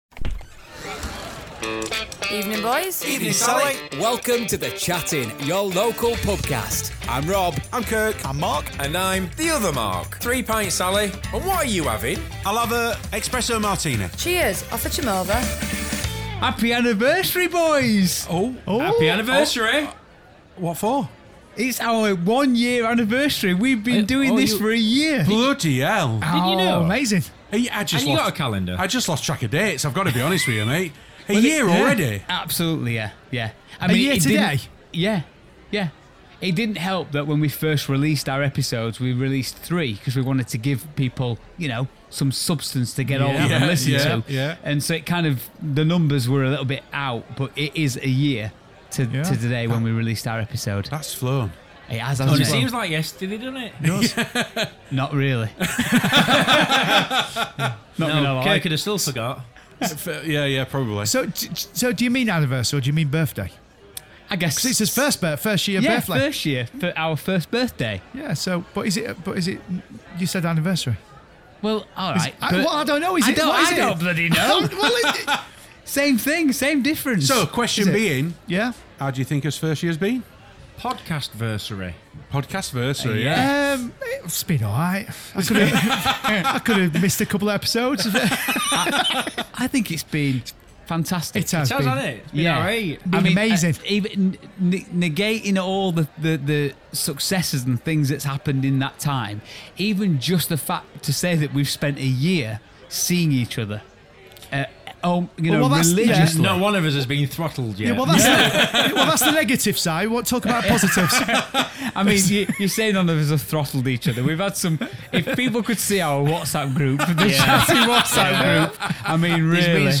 Welcome to "The Chat Inn" a podcast from the cozy corner of your favourite local pub, where four young at heart, middle-aged men gather to discuss, debate, and muse on a wide range of topics.
As you listen, you'll feel like your part of the lively banter that unfolds with each episode.